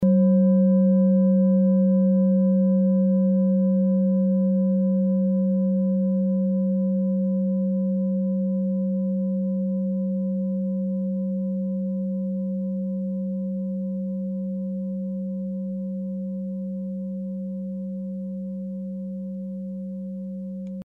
Orissa Klangschale Nr.16, Planetentonschale: Mondkulmination
(Ermittelt mir dem Filzklöppel)
Der Klang einer Klangschale besteht aus mehreren Teiltönen.
Wie aus dem Tonspektrum hervorgeht, handelt es sich hier um eine Planetentonschale Mondkulmination.
Die Klangschale hat bei 188.23 Hz einen Teilton mit einer
klangschale-orissa-16.mp3